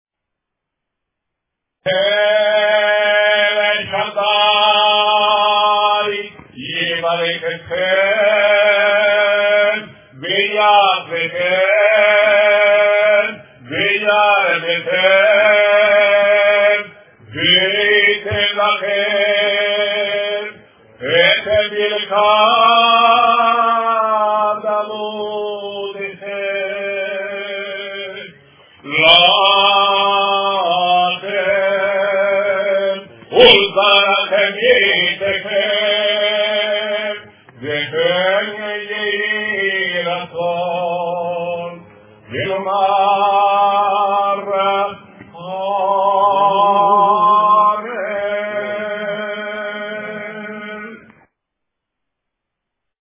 dal vivo